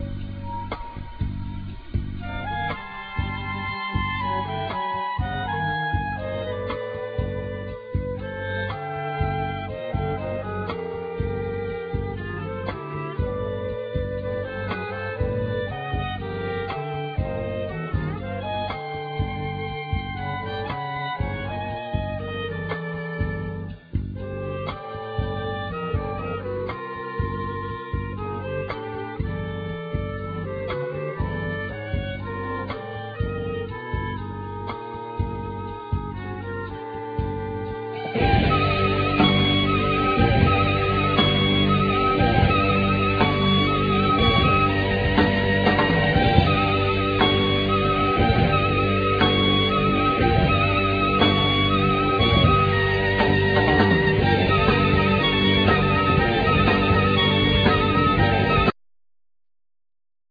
Keyboards
Bass
Guitar
Cello
Clarinet
Drums
Flute
Violin